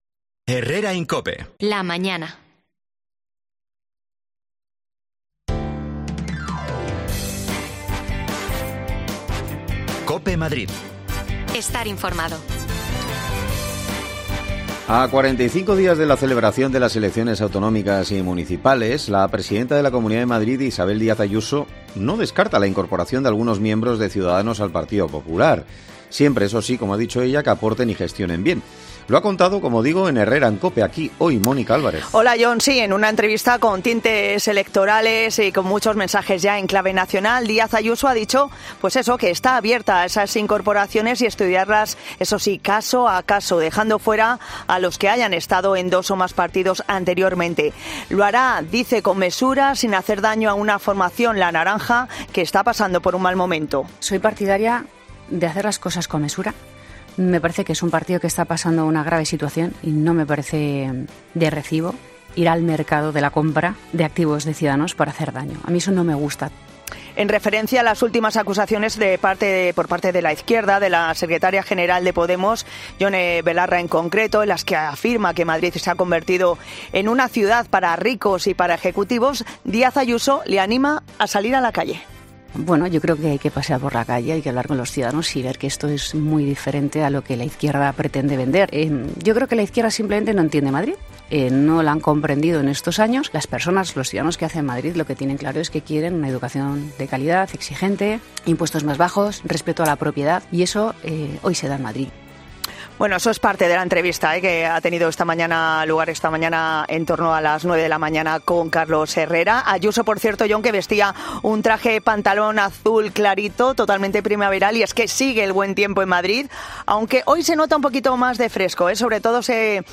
AUDIO: Isabel Diaz Ayuso, presidenta de Madrid inicia su campaña electoral en Cope. Destacamos lo más importante de su conversación con Carlos Herrera
Las desconexiones locales de Madrid son espacios de 10 minutos de duración que se emiten en COPE , de lunes a viernes.